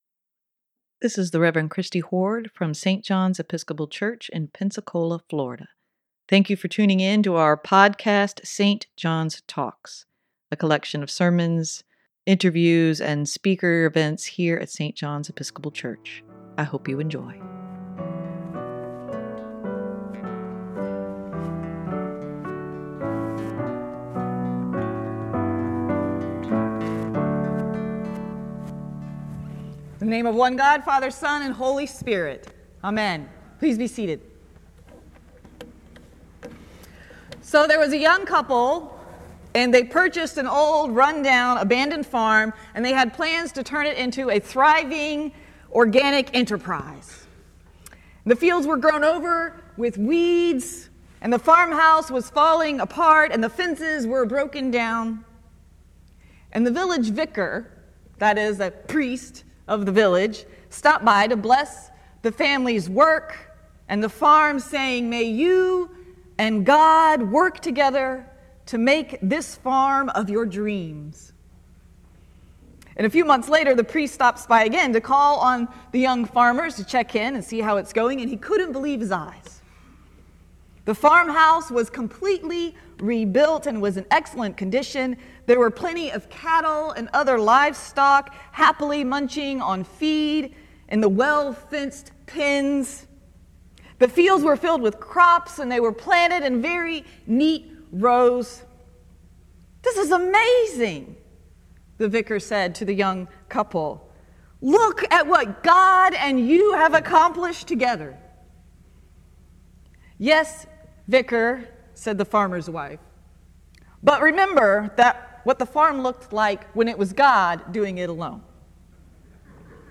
Sermon for Sunday, June 13, 2021 - St. John's Episcopal Church
sermon-6-13-21.mp3